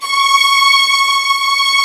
ZG3 STRS C#5.wav